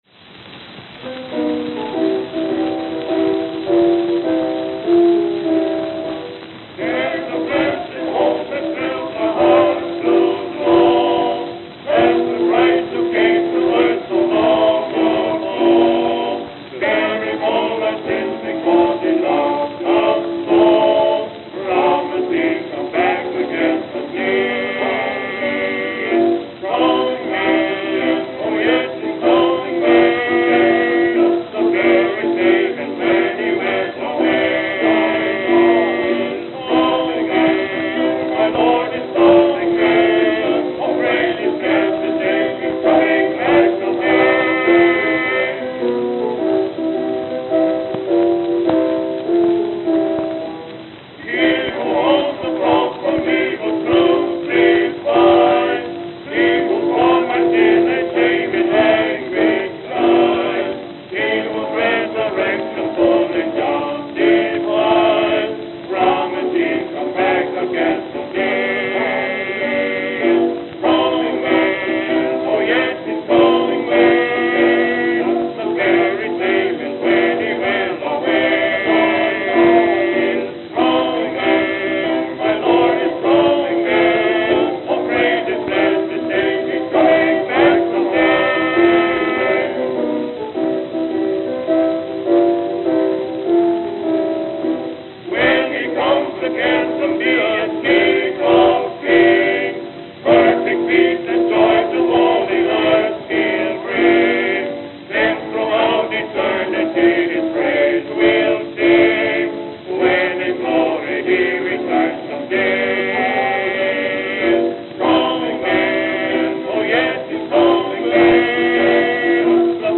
The following are records pressed by commercial record companies for private parties, usually for a "small" fee and a minimum order of pressed copies.
The aural quality of such recordings, especially acoustical ones, are usually not on par with commercial releases since the performers and their instruments were not well-trained for the finicky recording equipment of the time.
The Christian and Missionary Alliance Gospel Quintette The Christian and Missionary Alliance Gospel Quintette
Note: More bass left in
Here, but alot of the performance gets lost in it.